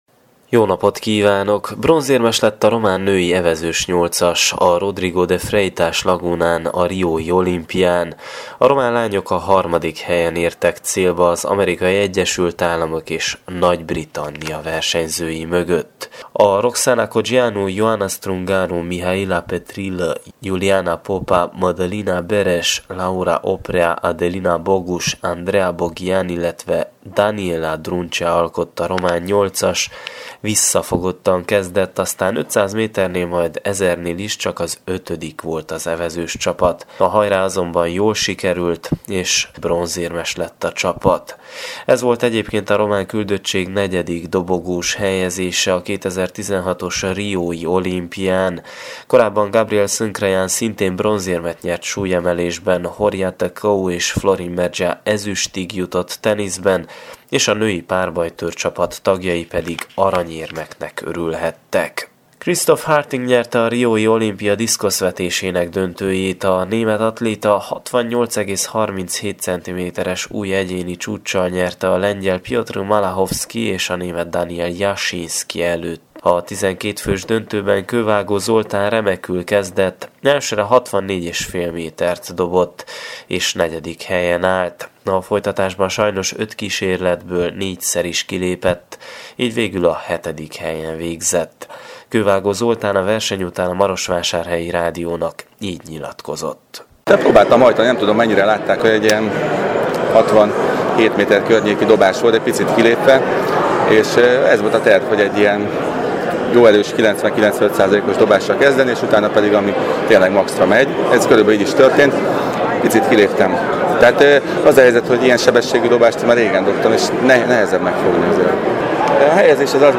Tudositas-olimpia.mp3